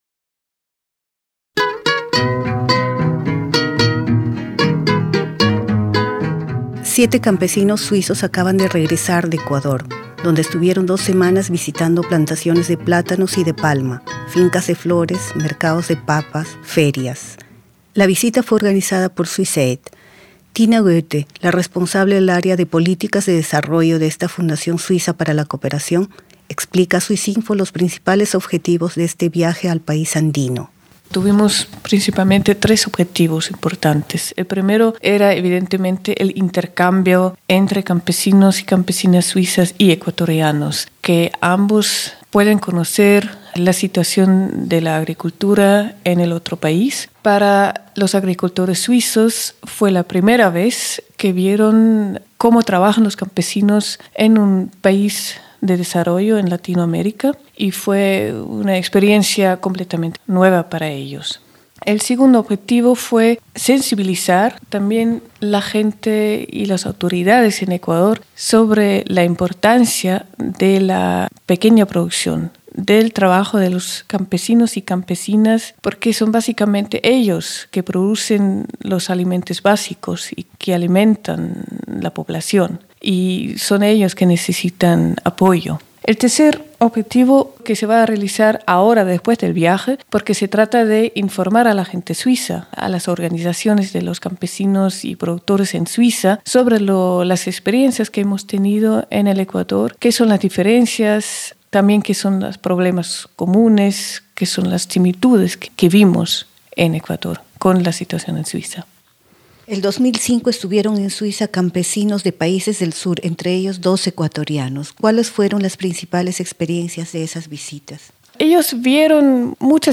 Un grupo de campesinos suizos acaba de retornar de Ecuador. La visita fue organizada por Swissaid, fundación suiza de cooperación al desarrollo que este año ha lanzado la campaña ‘El hambre no es una fatalidad’ Los suizos intercambiaron con sus pares ecuatorianos experiencias agrícolas y estrecharon los lazos que se tendieron por primera vez en 2005, cuando campesinos del sur, entre ellos dos ecuatorianos, visitaron Suiza. Reportaje